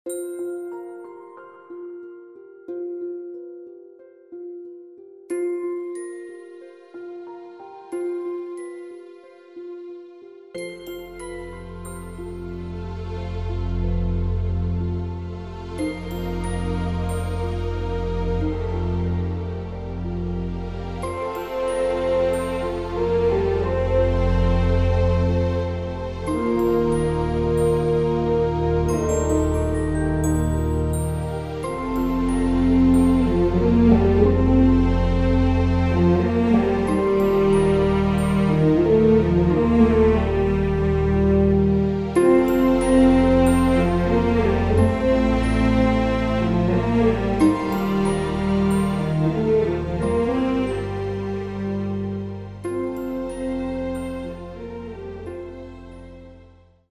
delightfully wicked score